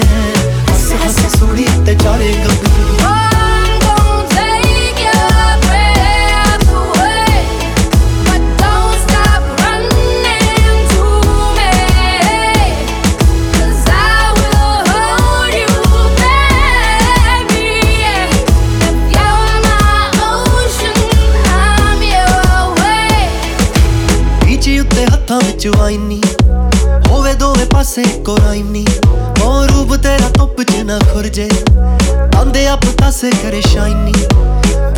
Жанр: Поп / Инди
# Indian Pop